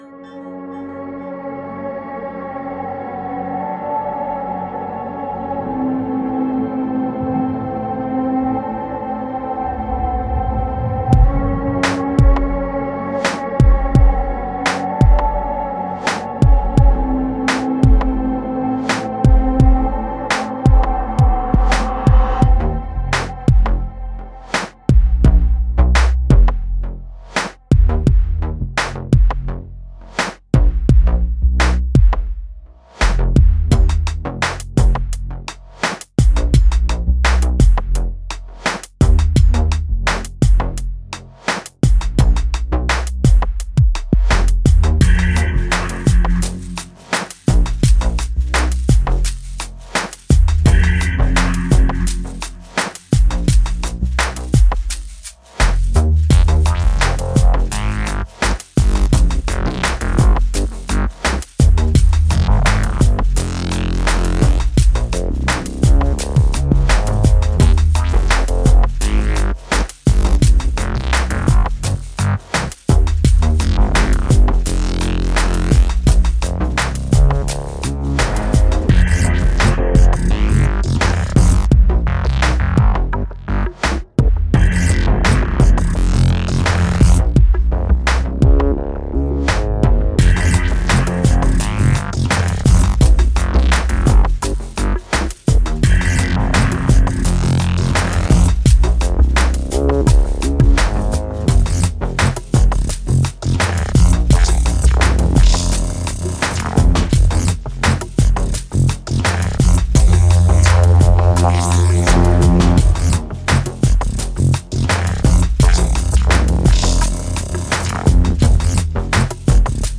Genre: IDM, Glitch, Downtempo.